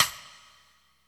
123__RIM.WAV